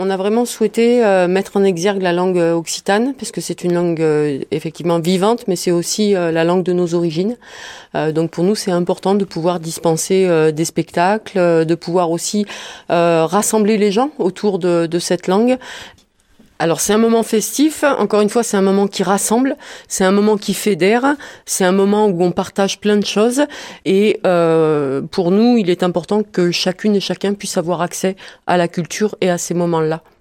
A partir de demain Marvejols fête la culture Occitane ! Un événement important de la saison et de  la vie Marvejolaise explique Patricia Brémond, la maire de la commune.